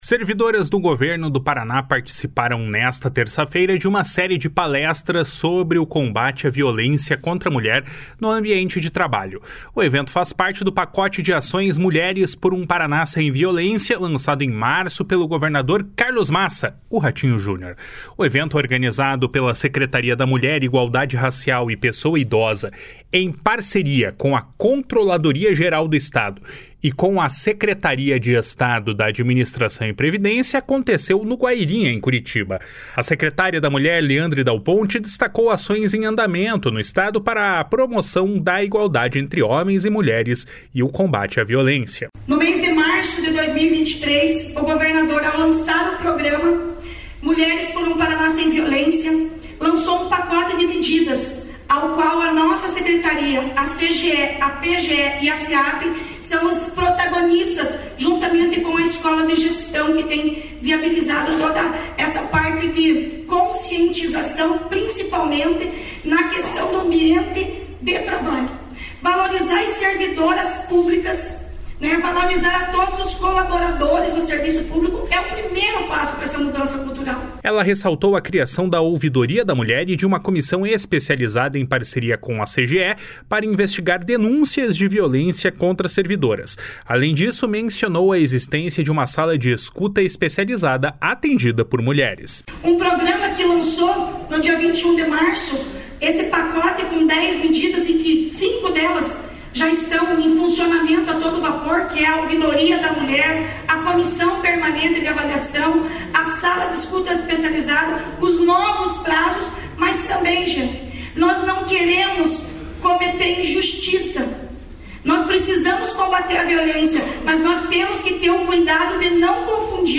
A secretária da Mulher, Leandre Dal Ponte, destacou ações em andamento no Estado para a promoção da igualdade entre homens e mulheres e combate à violência. // SONORA LEANDRE DAL PONTE //